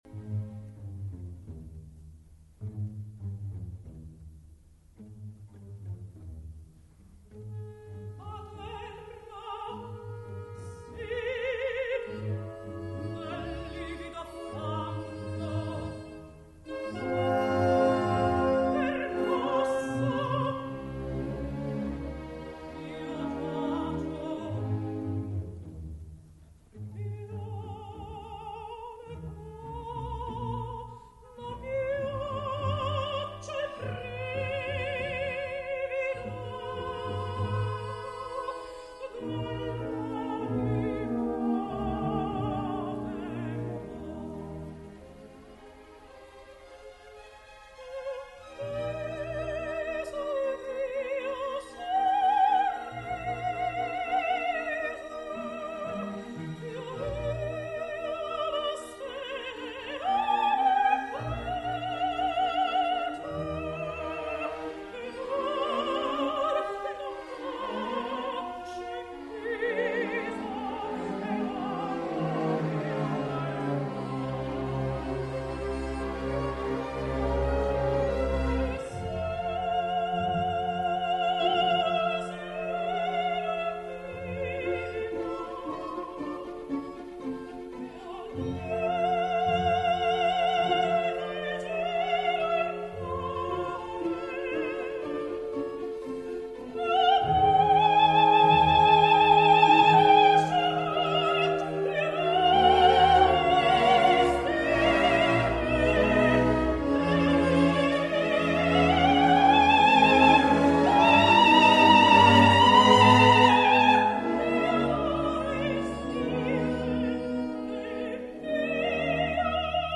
Desdemona [Sopran]